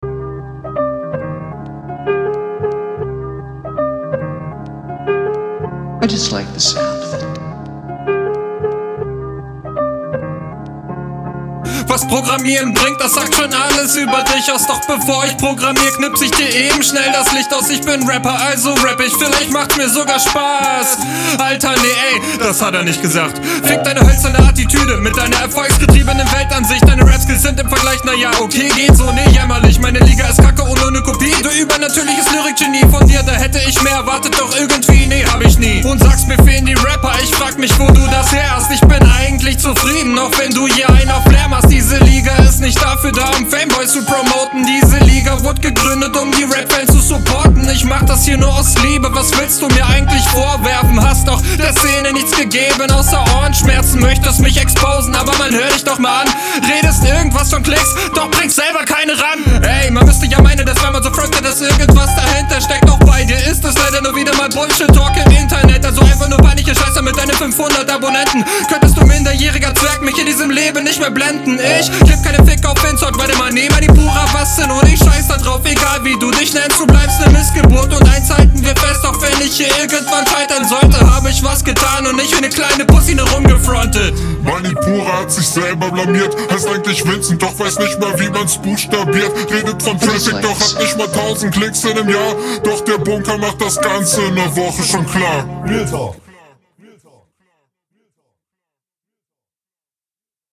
"hatta nicht gesagt" find ich nicht so geil delivert als Pointe. "...Attitüde" etwas offbeat.